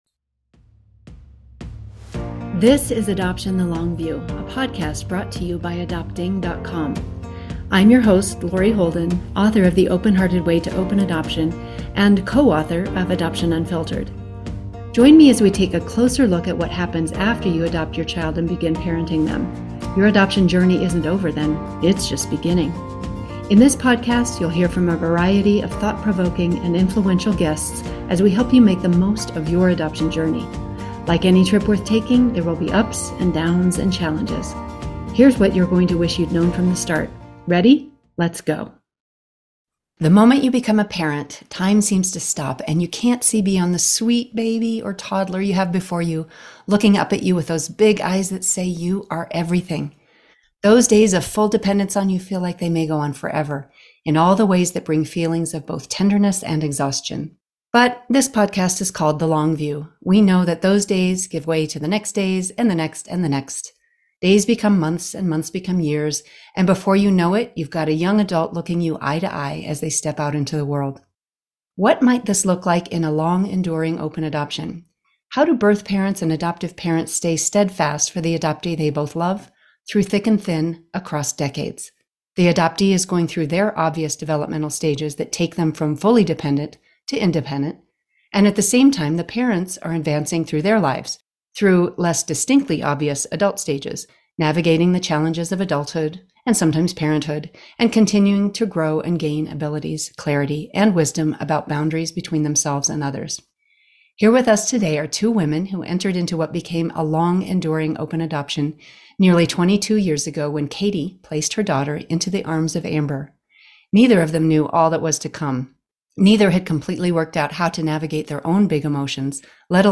How to create and sustain an adoptee-oriented open adoption for 22 years? A birth mom and adoptive mom share ways of being in relationship.